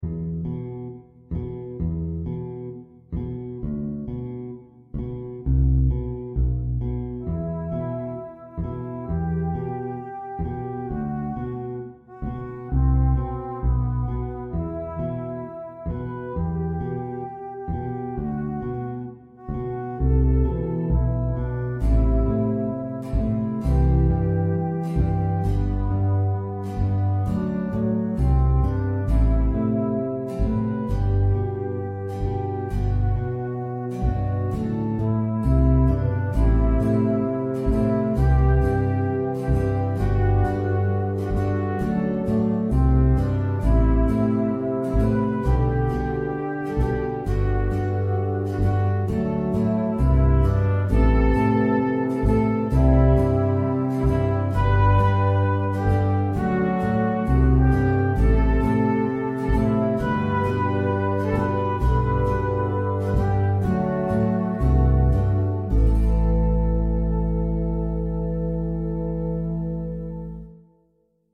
nostalgic instrumental piece